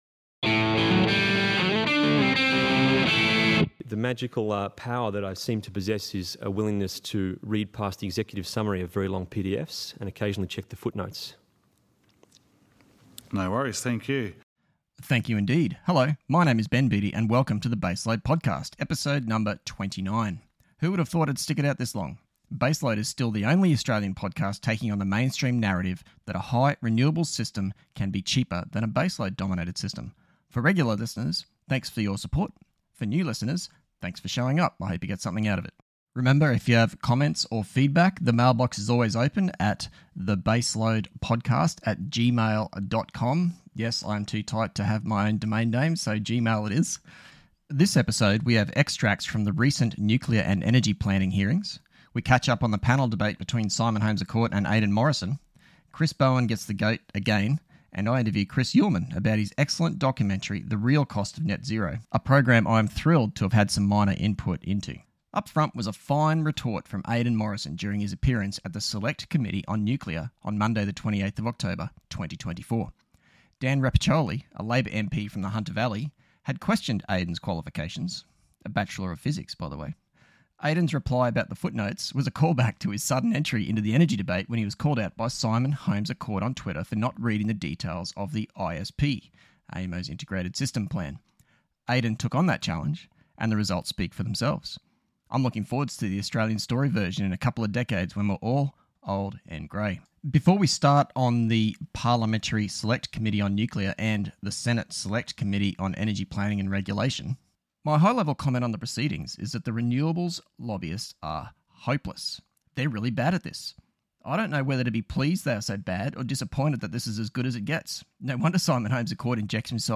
Interview: Chris Uhlmann – The Real Cost of Net Zero